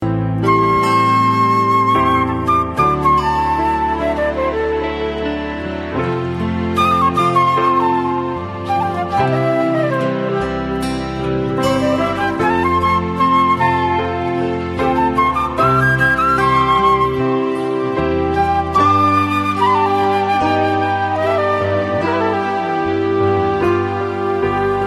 Alternative Ringtones